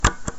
描述：按下NES按钮的声音（打开NES）
Tag: 任天堂NES 视频游戏 按键